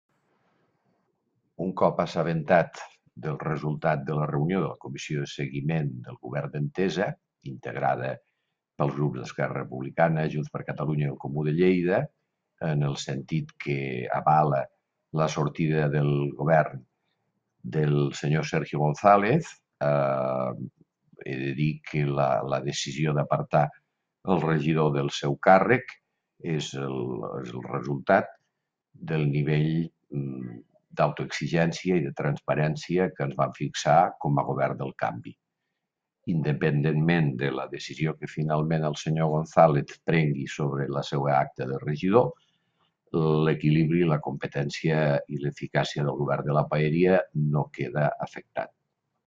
Tall de veu de Miquel Pueyo Compartir Facebook Twitter Whatsapp Descarregar ODT Imprimir Tornar a notícies Fitxers relacionats Tall de veu del paer en cap, Miquel Pueyo (1.9 MB) T'ha estat útil aquesta pàgina?
tall-de-veu-del-paer-en-cap-miquel-pueyo